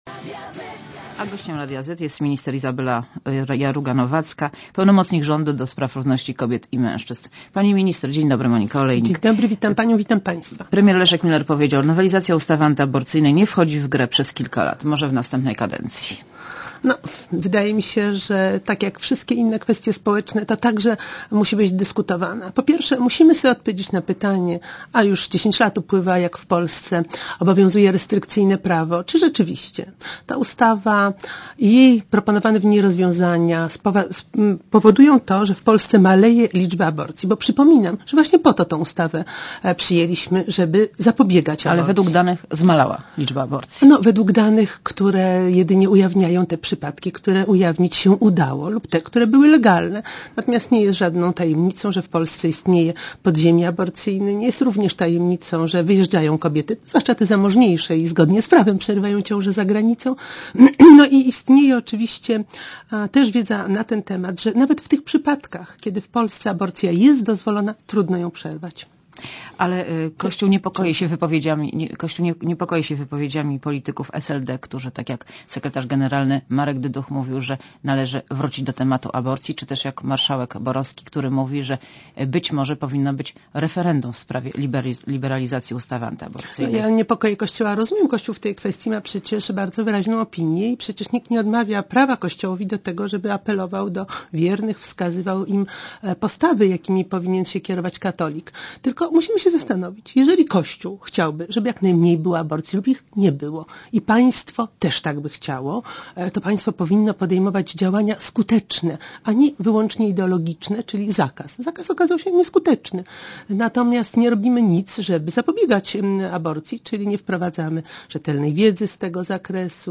Monika Olejnik rozmawia z Izabelą Jarugą-Nowacką - pełnomocnikiem rządu do spraw równouprawnienia kobiet i mężczyzn